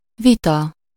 Ääntäminen
France: IPA: [yn kə.ʁɛl]